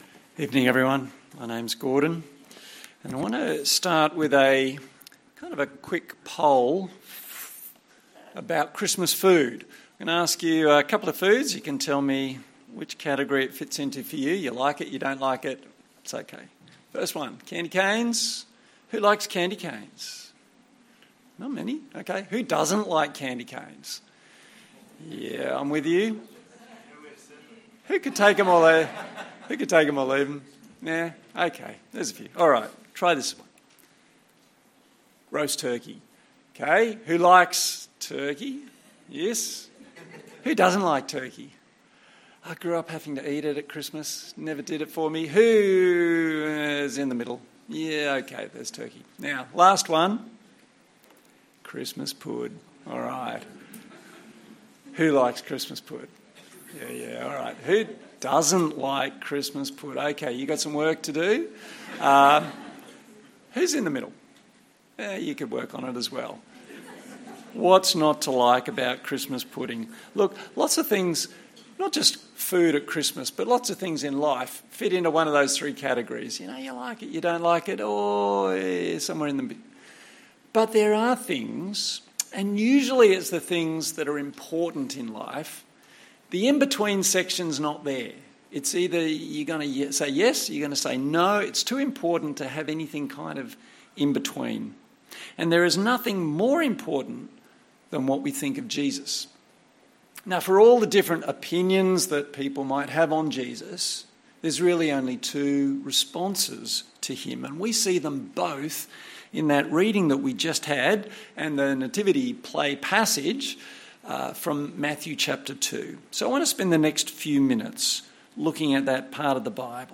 Family Christmas Service Passage: Matthew 2:1-18 Service Type: Sunday Evening